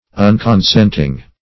unconsenting - definition of unconsenting - synonyms, pronunciation, spelling from Free Dictionary